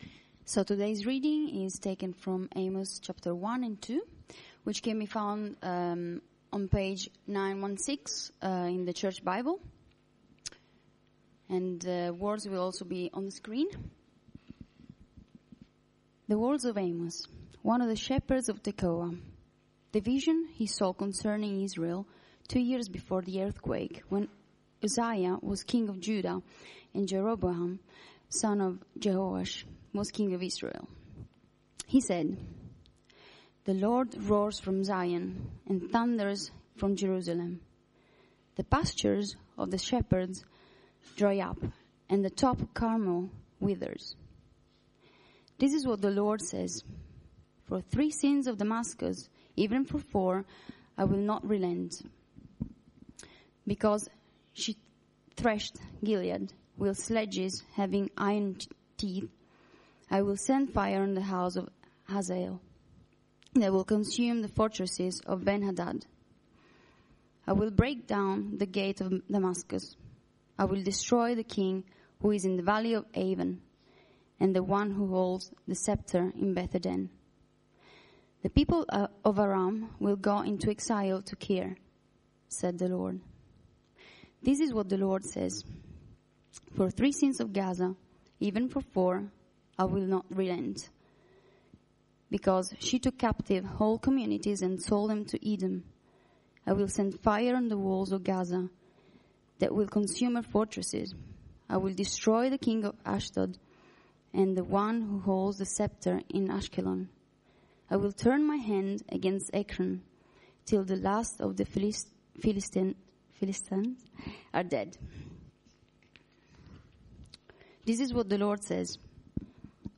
Media for Sunday Service
Sermon